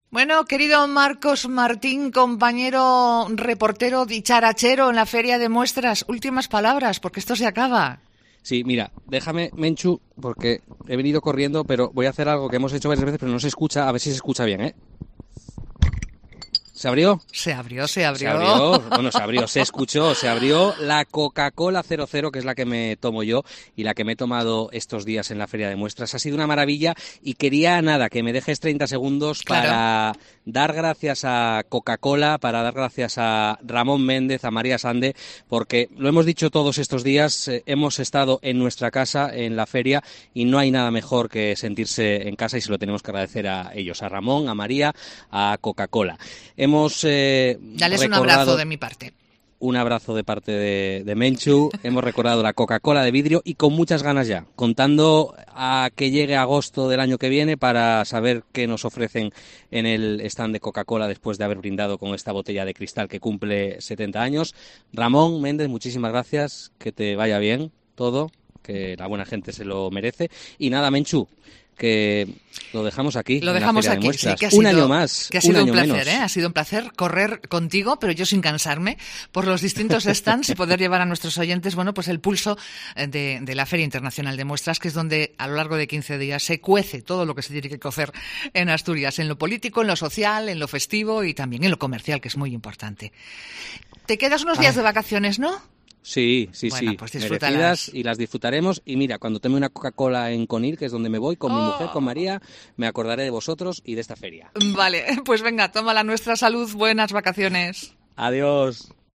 COPE Asturias ha cerrado la programación especial desde la Feria de Muestras de Asturias 2023 como la inició: desde el stand de Coca-Cola, la "casa" de la radio durante estos días.